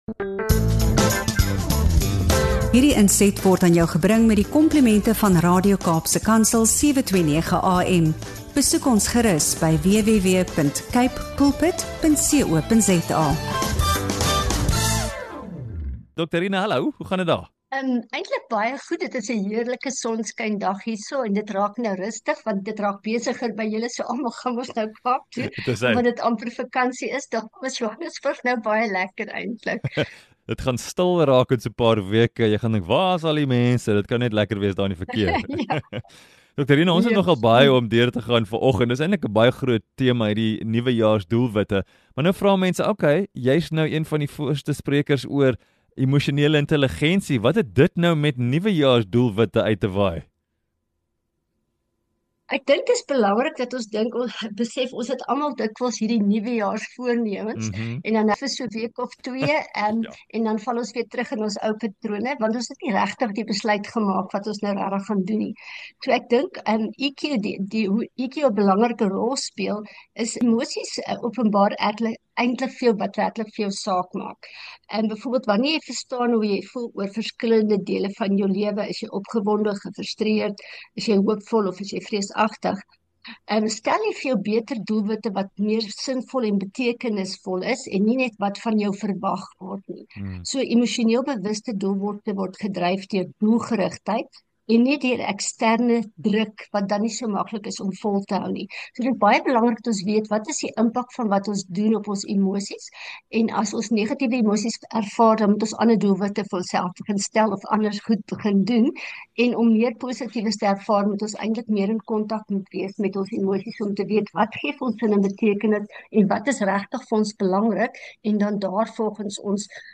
Hierdie gesprek bied waardevolle leiding vir almal wat hul emosionele welstand en doelwitbereiking in 2025 wil versterk.